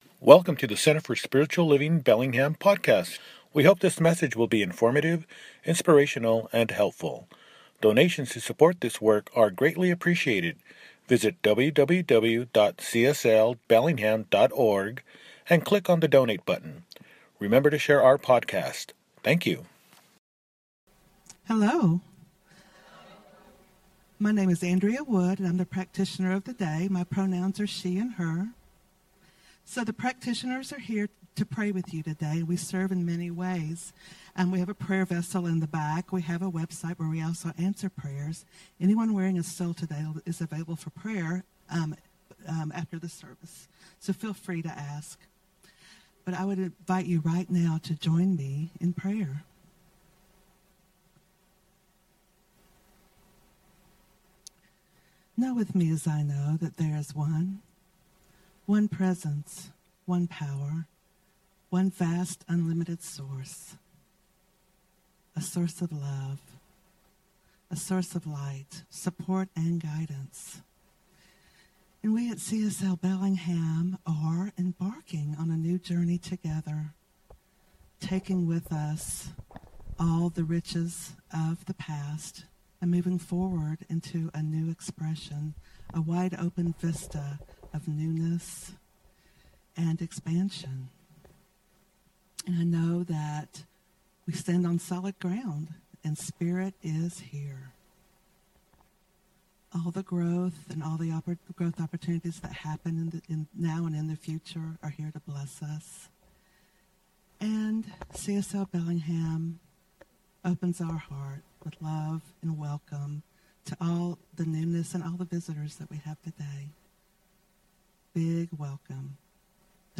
The Sacred Yes – Celebration Service